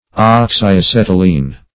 oxyacetylene - definition of oxyacetylene - synonyms, pronunciation, spelling from Free Dictionary
oxyacetylene \ox`y*a*cet"y*lene\, a.